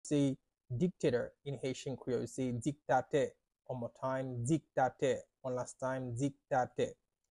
“Dictator” in Haitian Creole – “Diktatè” pronunciation by a native Haitian tutor
“Diktatè” Pronunciation in Haitian Creole by a native Haitian can be heard in the audio here or in the video below:
How-to-say-Dictator-in-Haitian-Creole-–-Diktate-pronunciation-by-a-native-Haitian-tutor.mp3